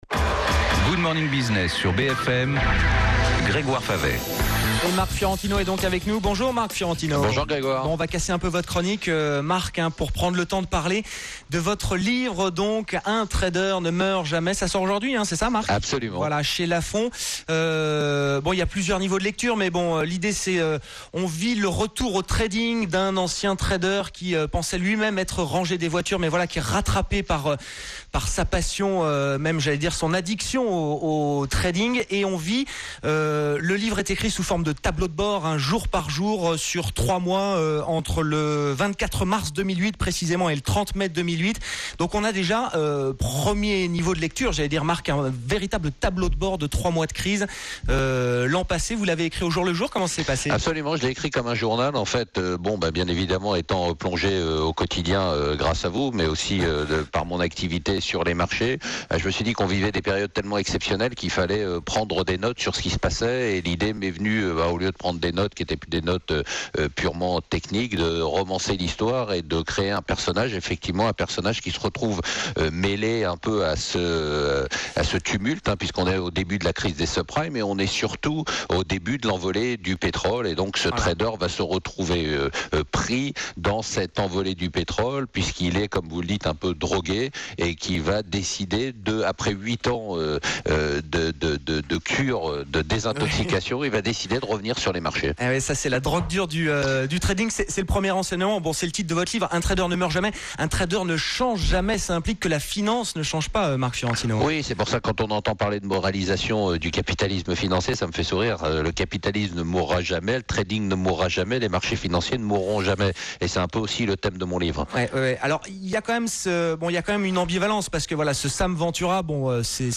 Interview Marc Fiorentino radio BFM du 9 janvier